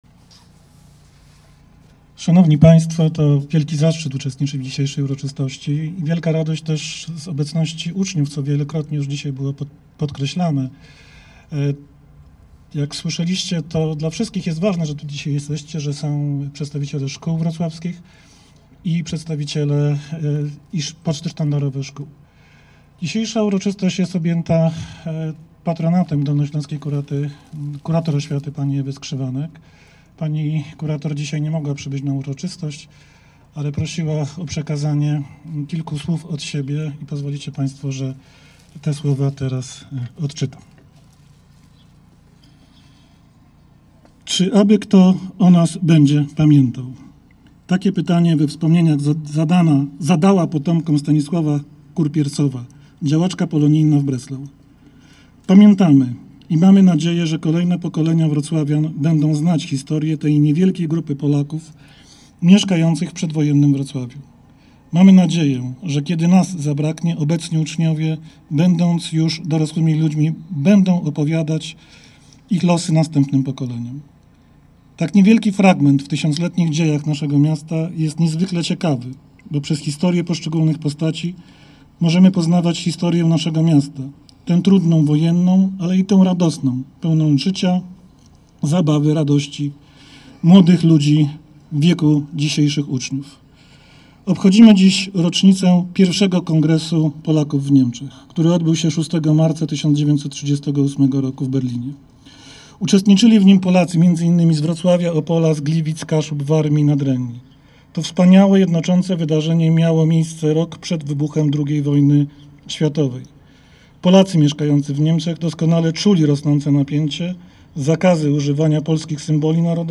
Uroczystości miały miejsce przy kościele św. Marcina na Ostrowie Tumskim we Wrocławiu.
W czasie uroczystości głos zabrali:
Dariusz Wójcik – Dolnośląski Wicekurator Oświaty w imieniu Honorowego Patrona Ewy Skrzywanek Dolnośląskiej Kurator Oświaty